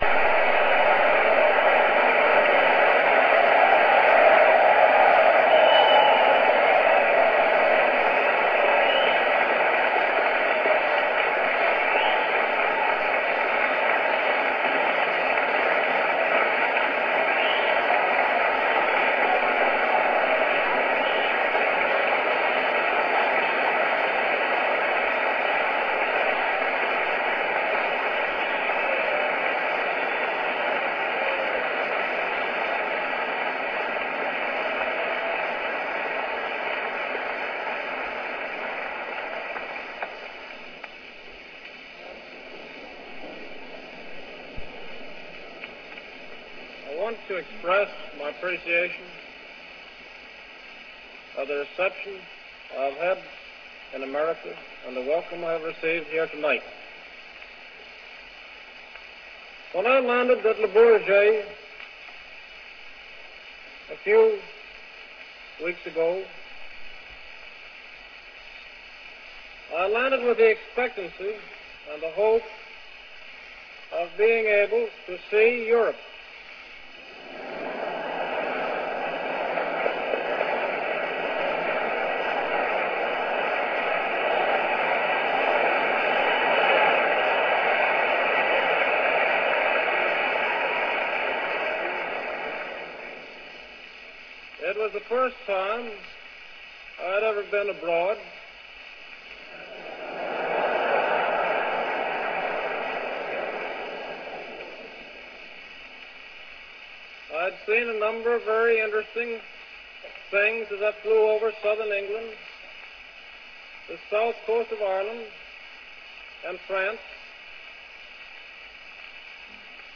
Spoken Word
Digital recording from Victor Talking Machine disc made at Menlo Park.
This is a recording of Lucky Lindy as he was called in front of the press as a world wide hero for his none stop flight across the Atlantic in 1927.